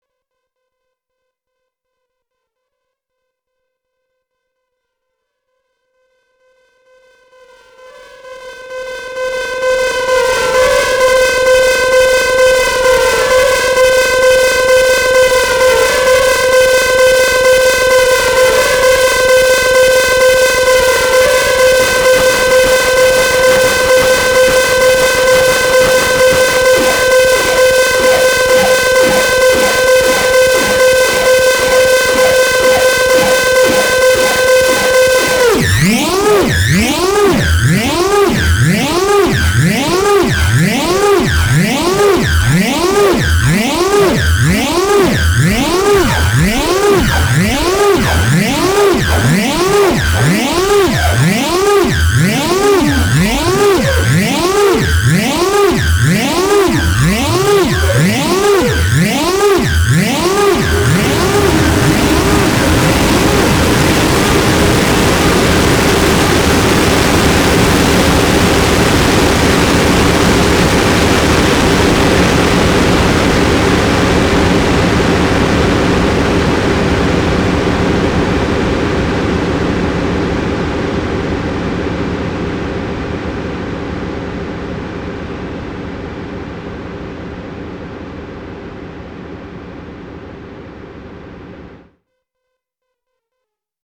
Now back to using delay feedback. No synths to kick anything off. LFO on reverb pre delay. I’m adjusting the delay feedback time and reverb decay.